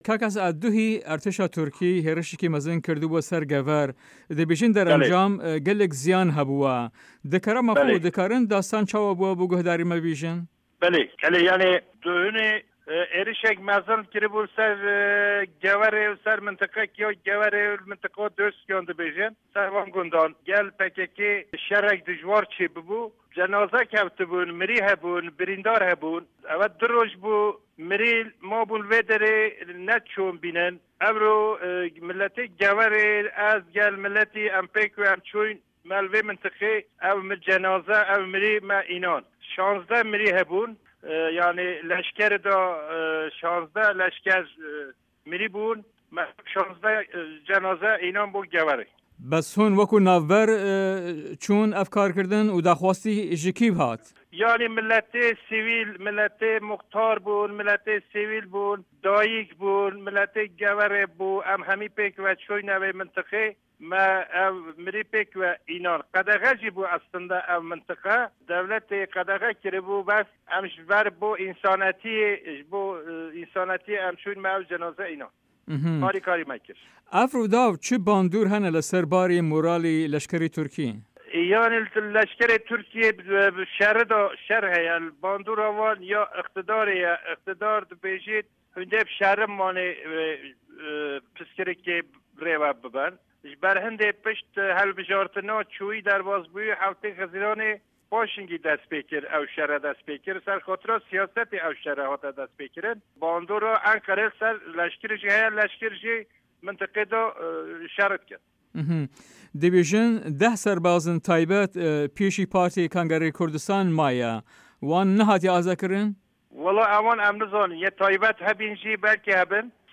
Hevpeyvîn bi Esat Canan re